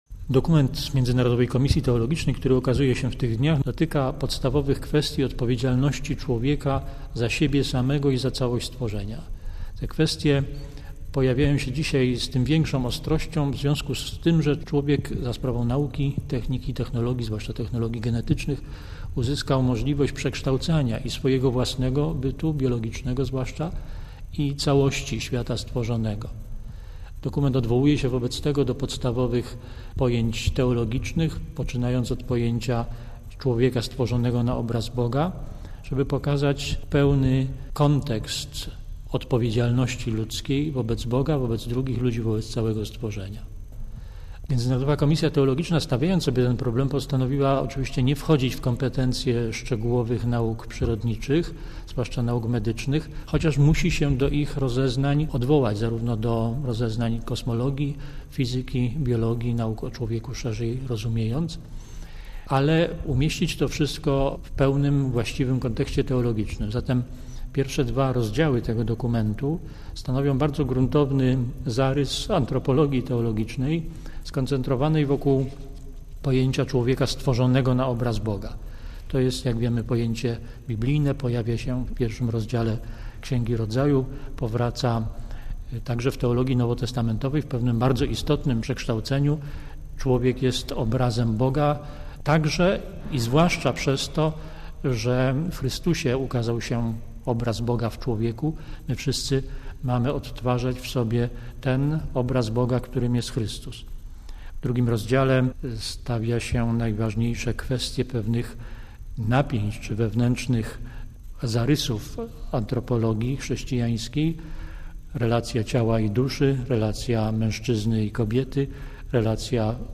Oto, co na ten temat powiedział naszej rozgłośni: